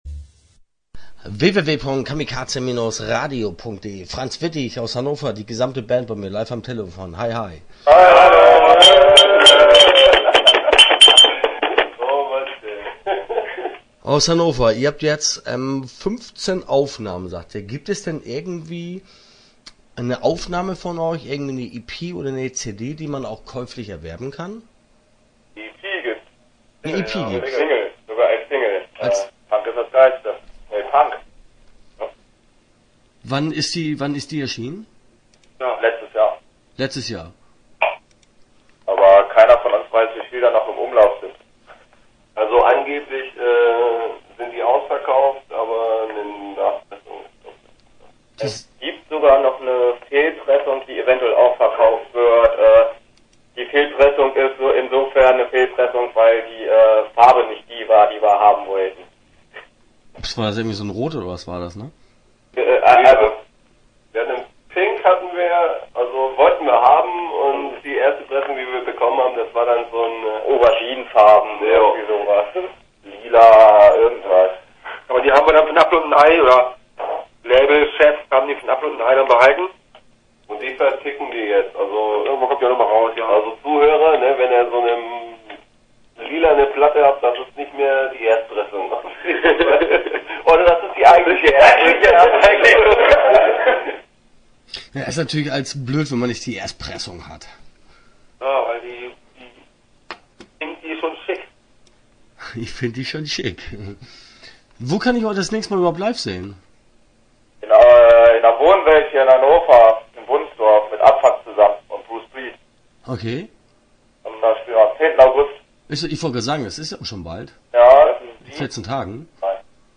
Interview Teil 1 (8:02)